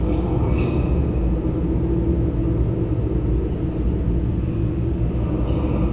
tunnel_wind3.wav